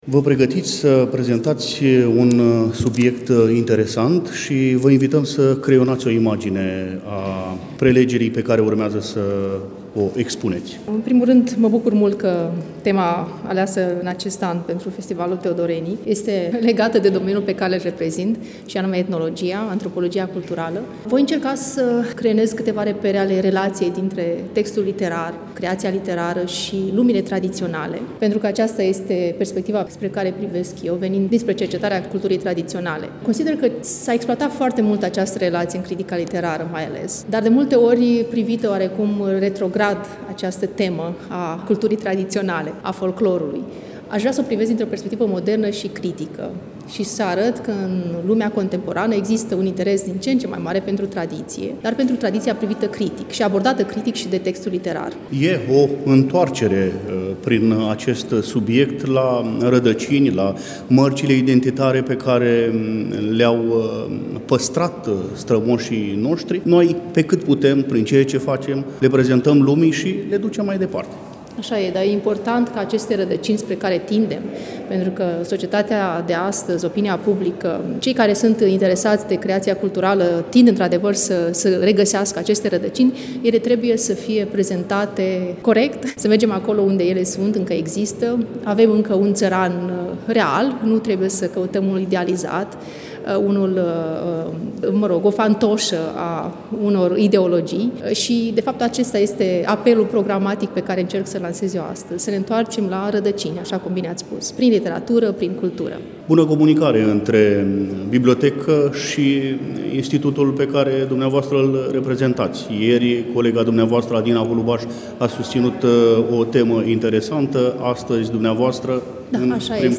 Relatăm de la ediția a X-a a Festivalului „Teodorenii”, eveniment desfășurat, la Iași, în perioada 10 – 12 decembrie 2024.